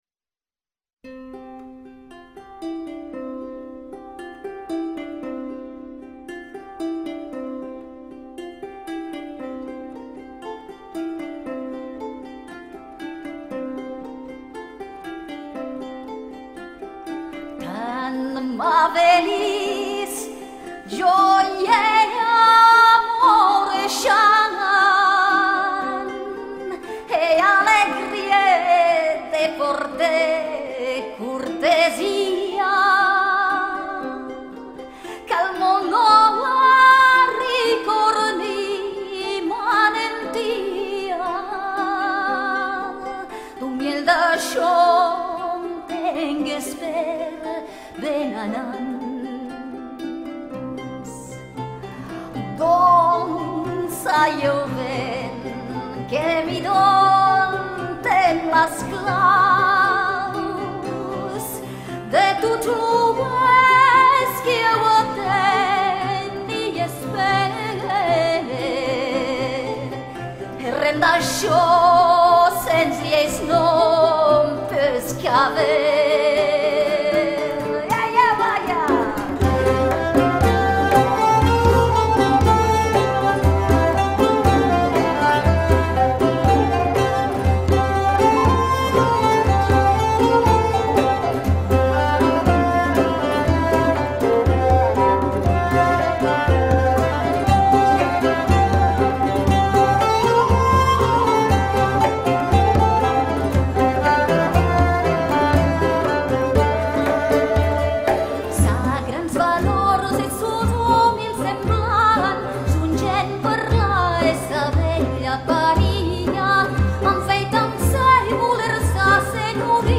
Playlist includes music from genres: Troubadour song